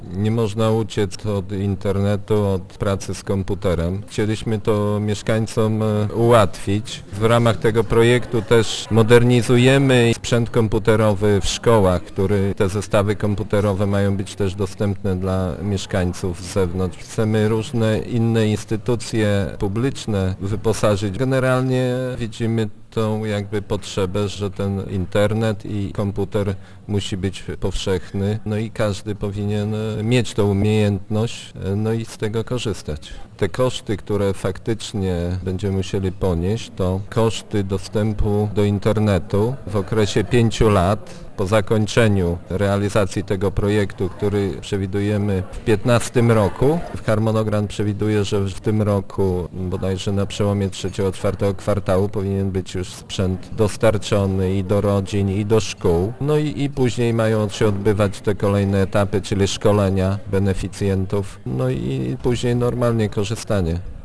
Wójt Gminy Głusk Jacek Anasiewicz nie ma wątpliwości, że realizacja tego projektu przyniesie mieszkańcom wymierne korzyści.